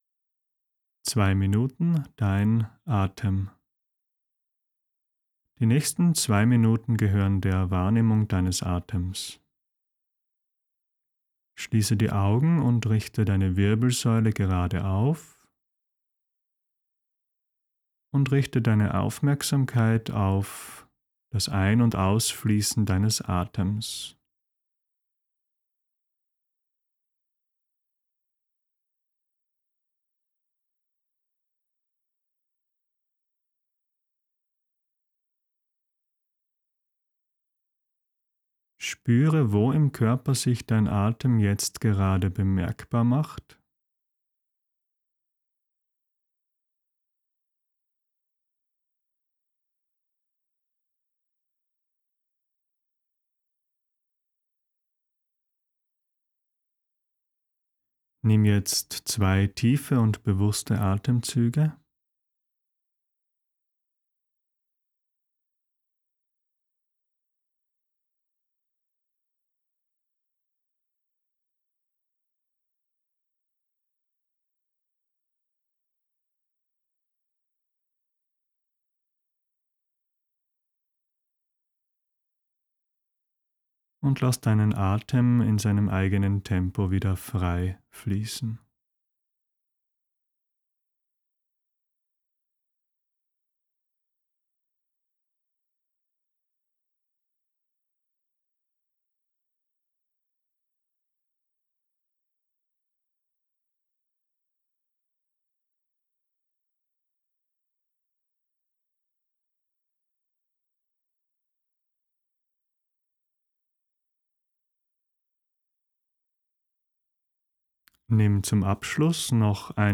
Zwei Minuten Atemachtsamkeit für Tage mit wenig Zeit und Lust. Audioanleitung und Erklärung zum Lesen.